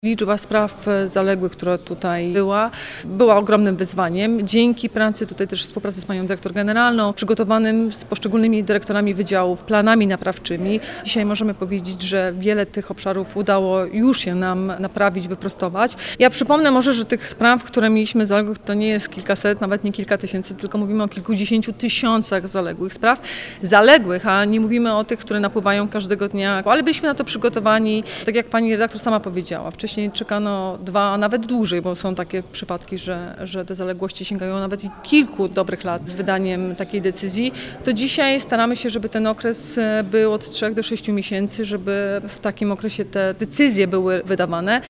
Dolnośląski Urząd Wojewódzki musiał usprawnić obsługę cudzoziemców, kontrola NIK wykazała bowiem kilkadziesiąt tysięcy zaległych wniosków w zakresie legalizacji pobytu czy zezwoleń na pracę. – Staramy się, aby decyzje ws. wniosków były wydawane okresie od 3 do 6 miesięcy – dodaje wojewoda dolnośląska.